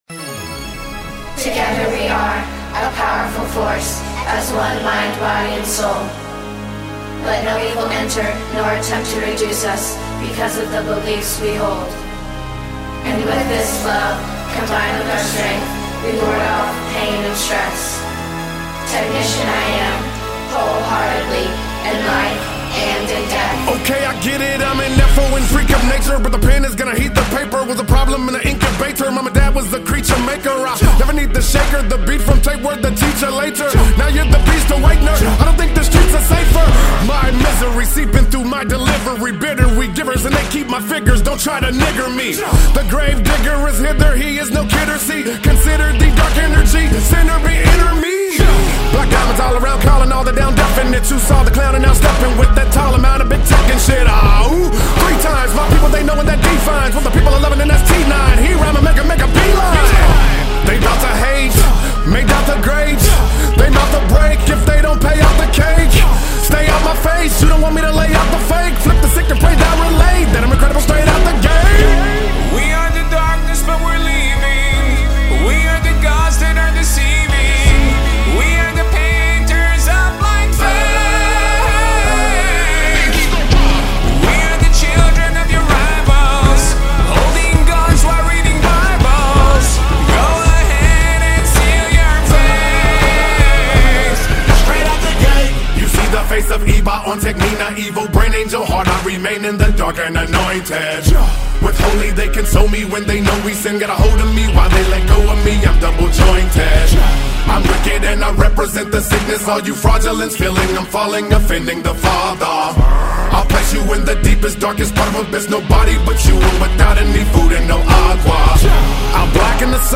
Gangsta Rap
Gangsta-Rap.mp3